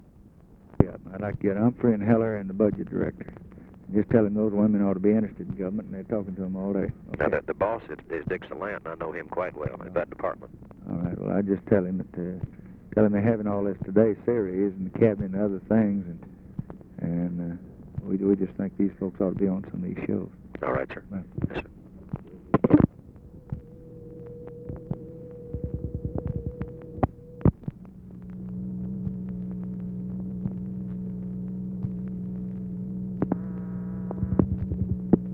Conversation with WALTER JENKINS, January 21, 1964
Secret White House Tapes